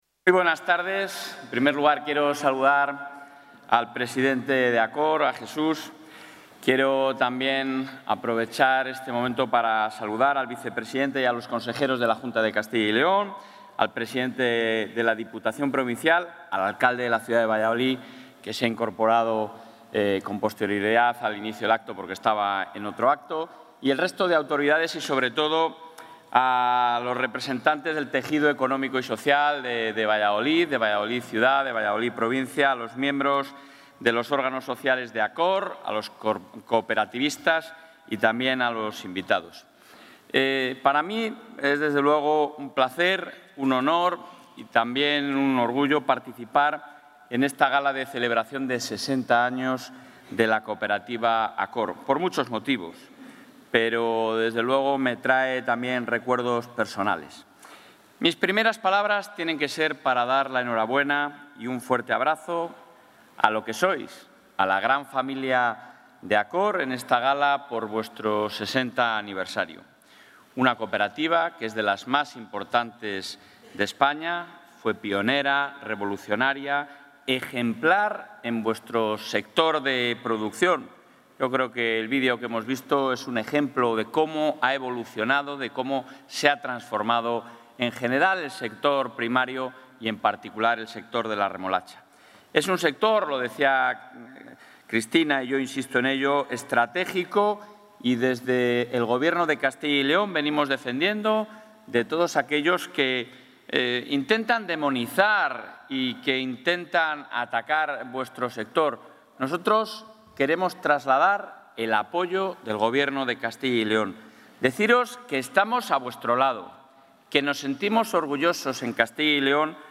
Intervención del presidente de la Junta.
El presidente de la Junta y Castilla y León ha participado en la Gala del 60 aniversario de la cooperativa ACOR, donde ha recordado que el 85 % de la molturación de la remolacha de España se realiza en la Comunidad, por lo que es un sector estratégico al que es necesario defender de ataques e intentos de demonización.